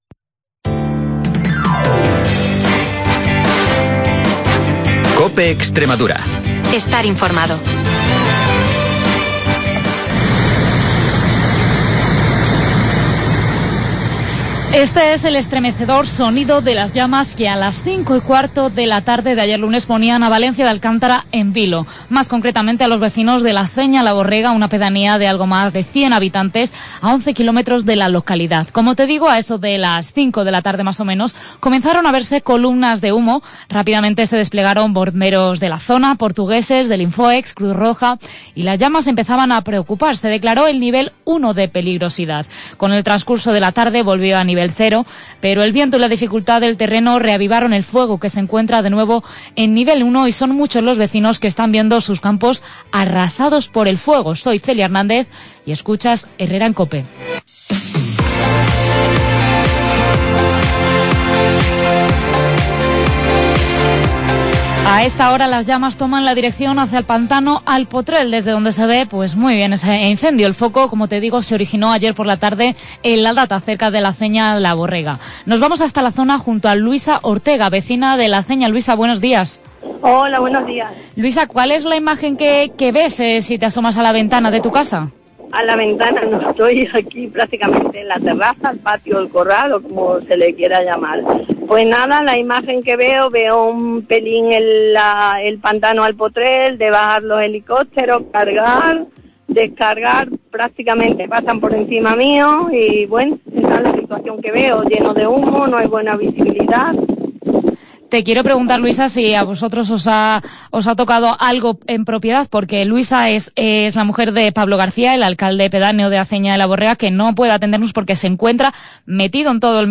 Una vecina de Aceña la Borrega cuenta